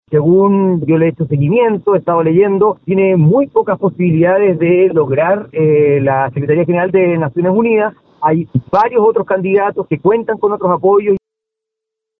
En esa misma línea, desde el Partido Republicano, el diputado Stephan Schubert expresó dudas sobre sus posibilidades, señalando que existen otros postulantes con mayores y mejores respaldos internacionales.